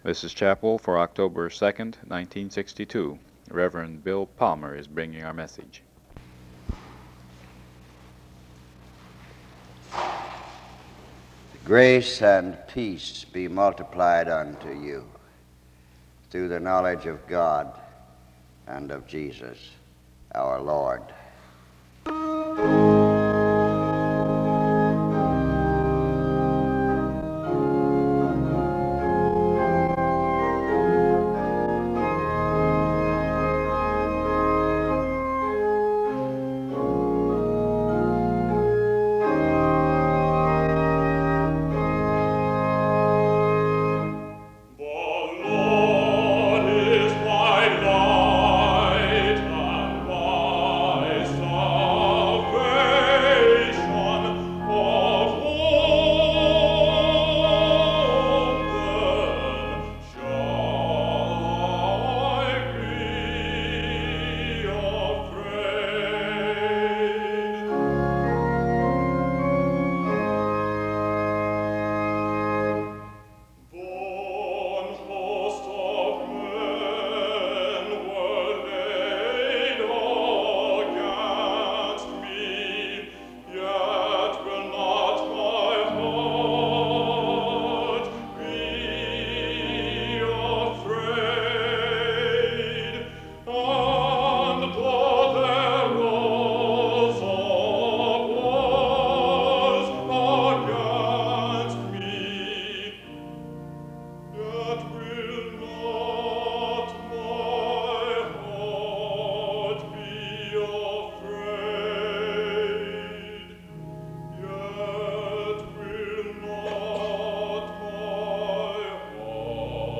The service begins with a hymn from 0:11-5:13.
There is closing music from 19:07-21:21.
Location Wake Forest (N.C.)